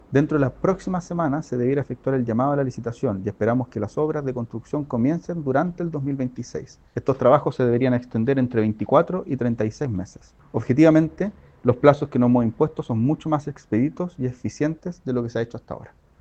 En conversación con Radio Bío Bío, el ministro de Justicia y Derechos Humanos, Jaime Gajardo, admitió que la ampliación de Santiago 1 finalmente comenzaría a principios de 2026, y se podría concretar entre 2028 y 2029, para ser habilitada recién en 2030.